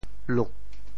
潮州府城POJ luk 国际音标 [lut]